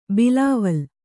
♪ bilāval